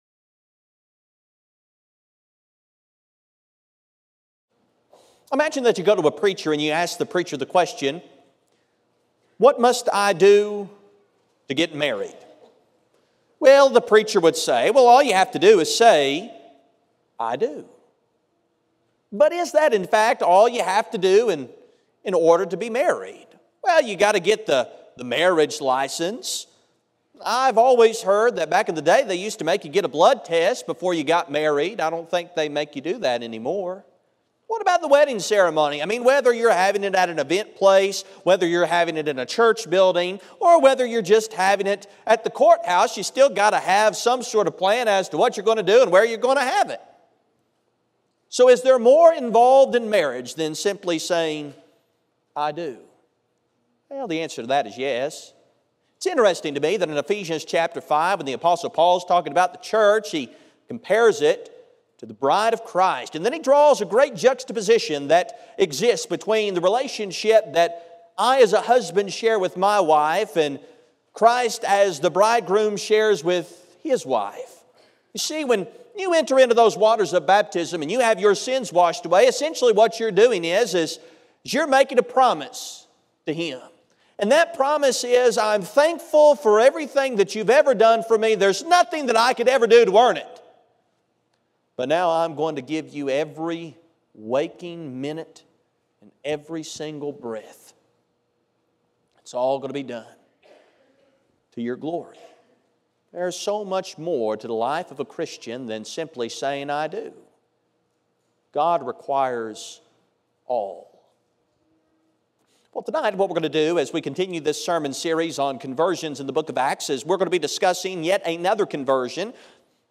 The sermon is from our live stream on 10/13/24